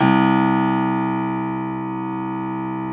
53h-pno02-C0.wav